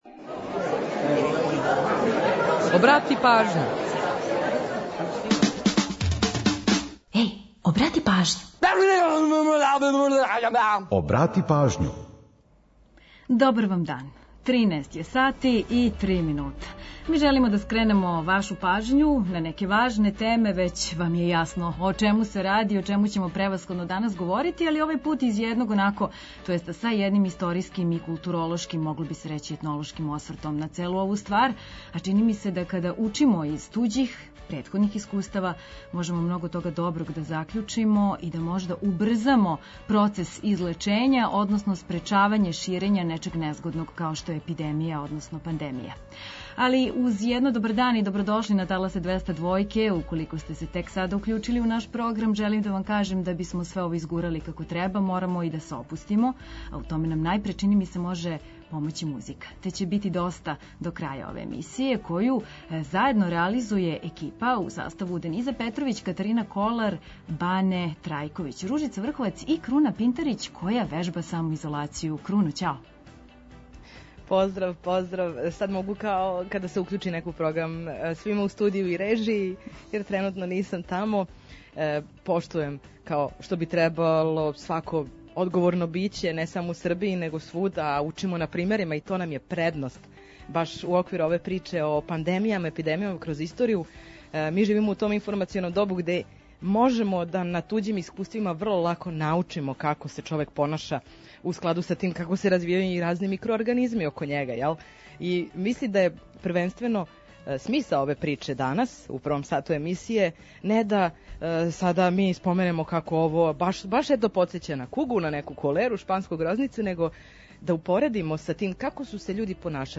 Сервисне информације и наш репортер са подацима о саобраћају помоћи ће многима у организовању дана.
Ту је и пола сата резервисаних само за нумере из Србије и региона.